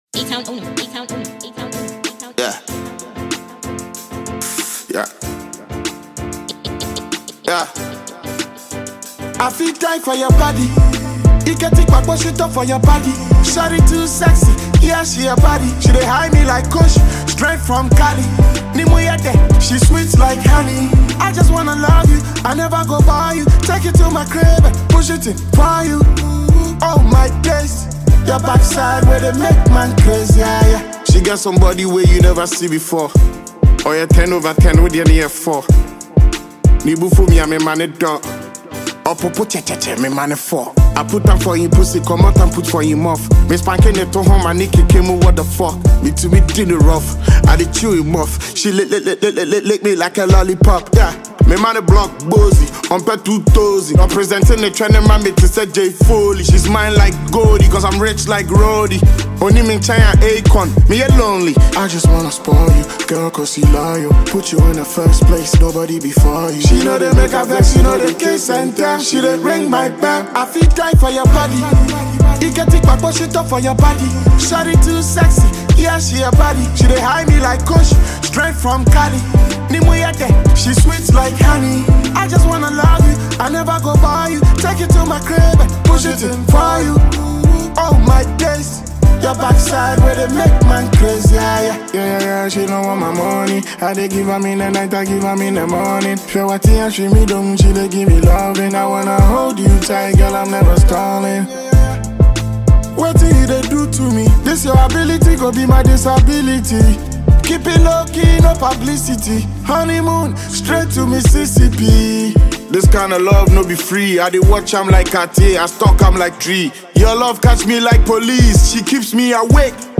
Heavyweight Ghanaian rapper